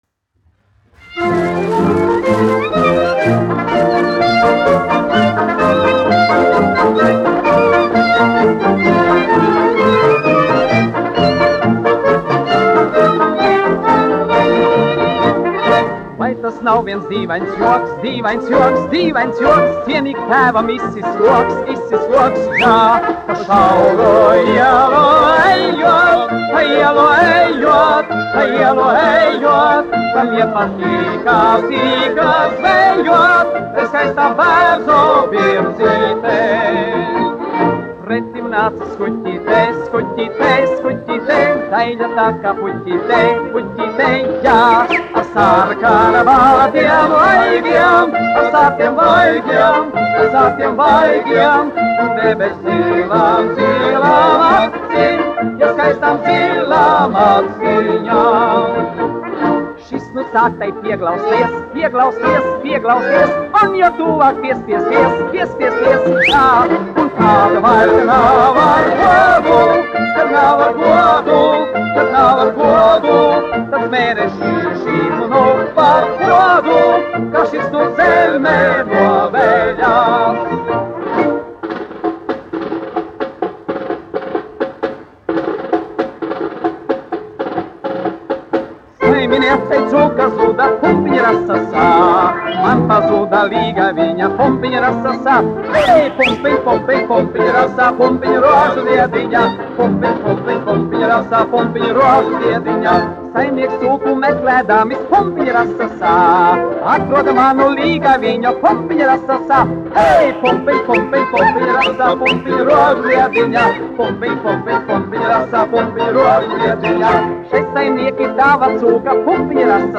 1 skpl. : analogs, 78 apgr/min, mono ; 25 cm
Kara dziesmas
Populārā mūzika -- Latvija